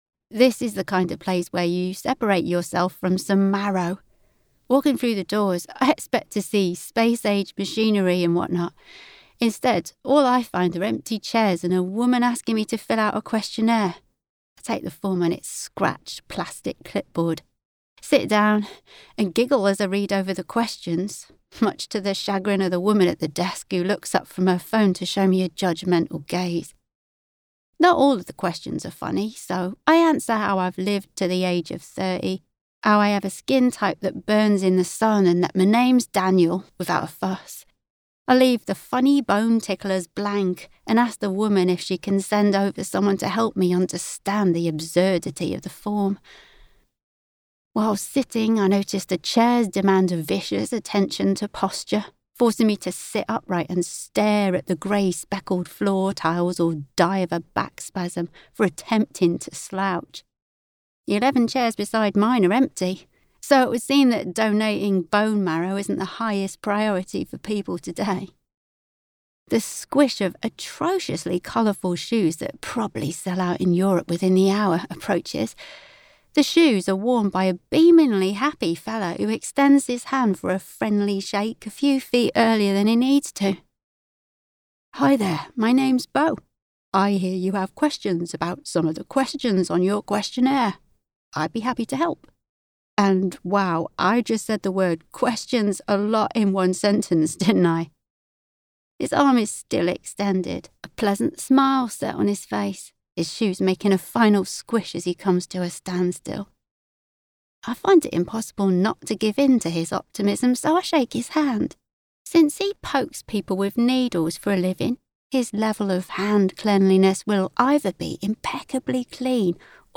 british, female